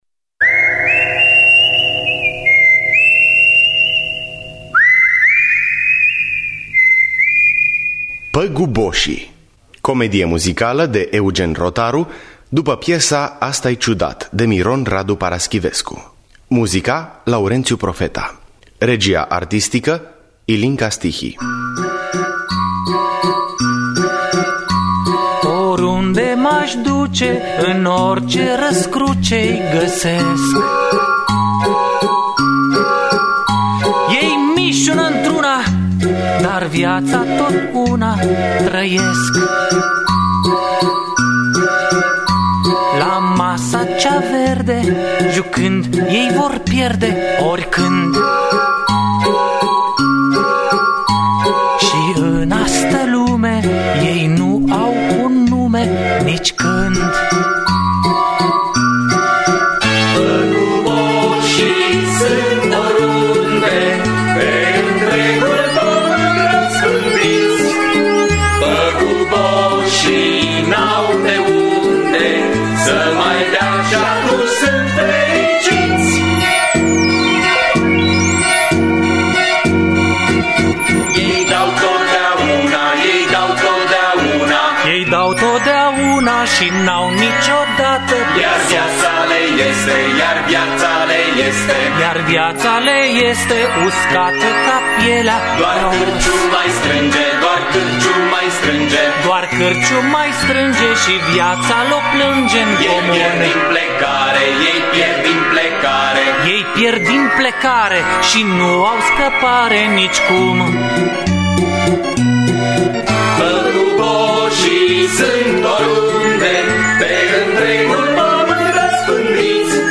Păguboșii de Miron Radu Paraschiv – Teatru Radiofonic Online